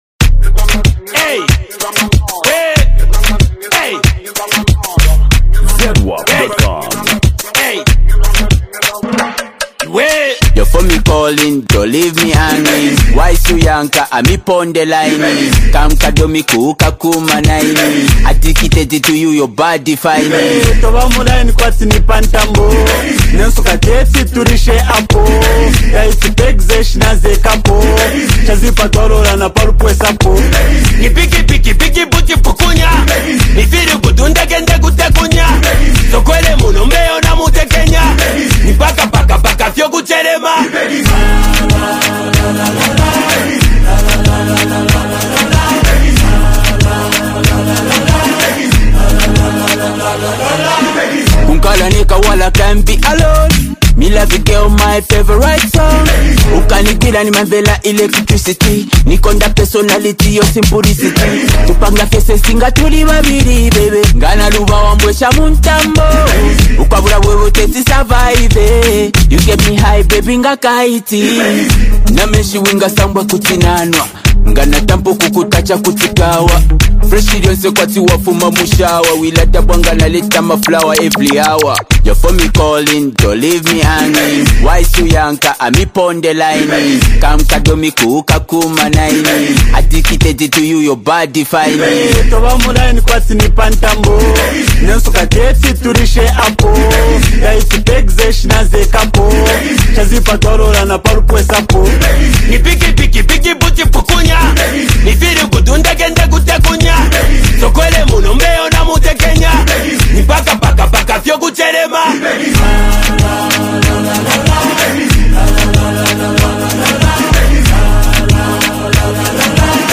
Genre: Zambian Music